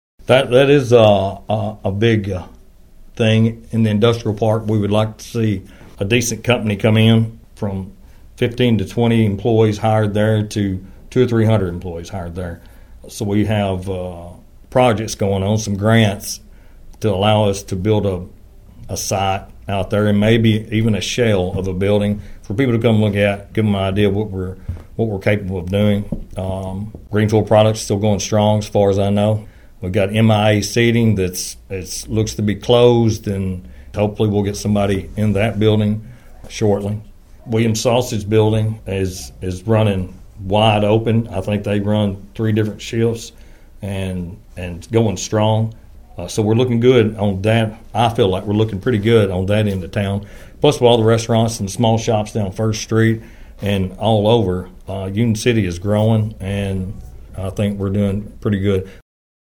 McTurner talked about other work that occurred during the year.(AUDIO)